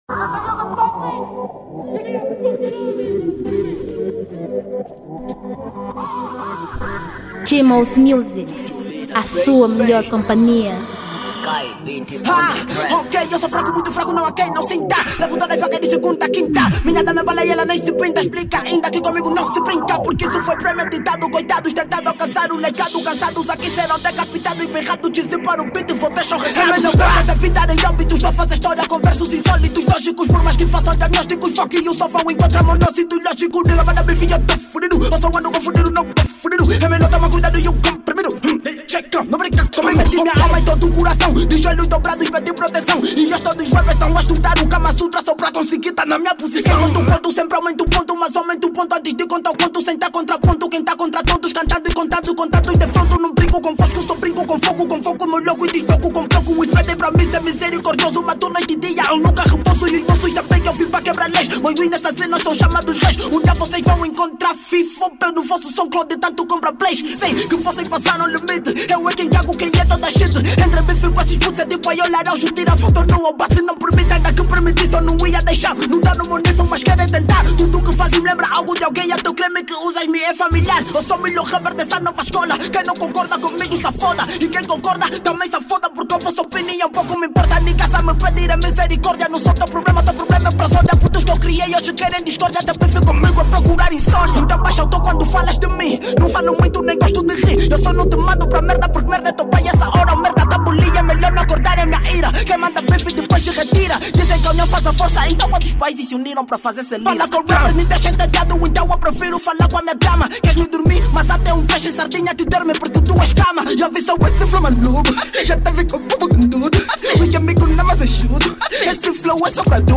Uma faixa do gênero Rap com um conteúdo extremamente duro.